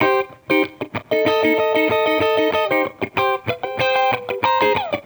Index of /musicradar/sampled-funk-soul-samples/95bpm/Guitar
SSF_TeleGuitarProc1_95C.wav